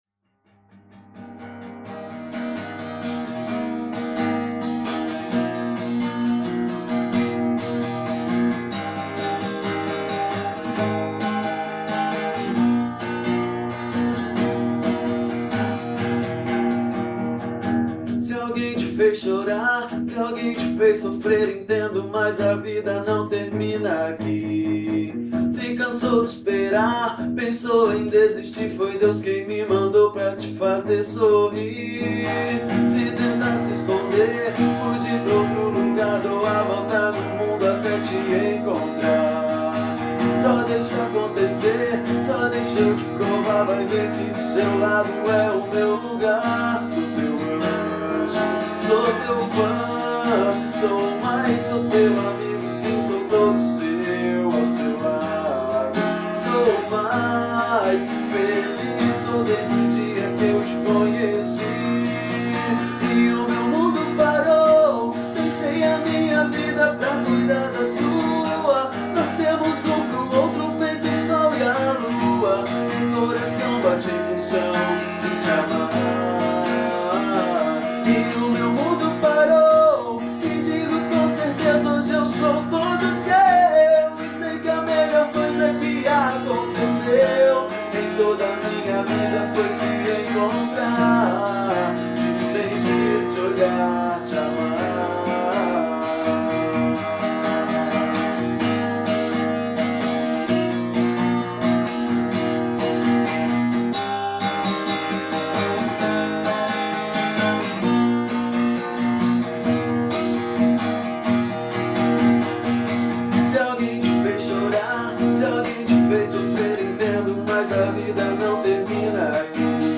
EstiloSertanejo